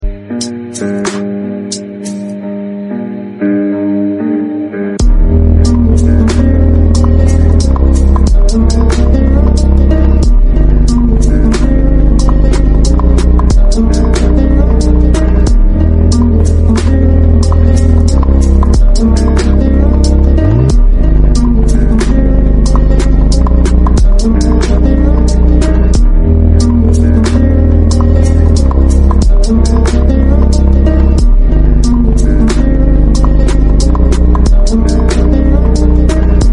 Check out this clean ford f150 in for muffler delete and resonator let us know how it came out sounding✅